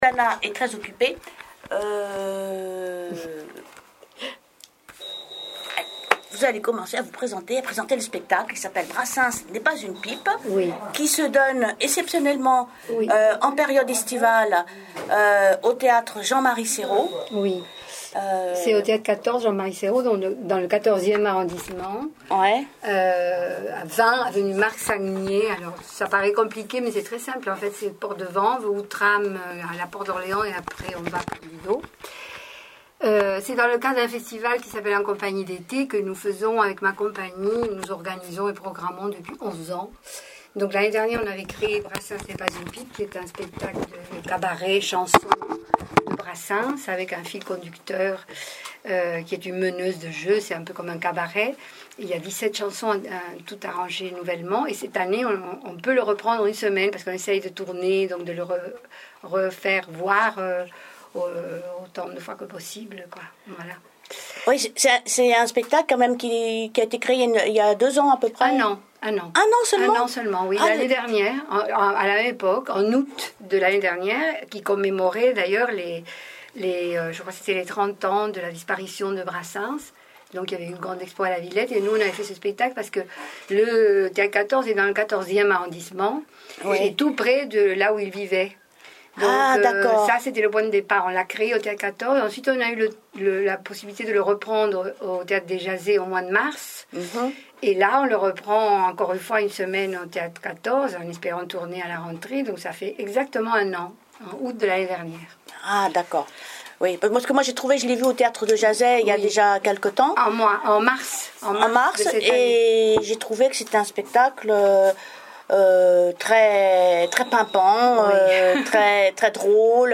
INTERVIEW ET EXTRAIT SPECTACLE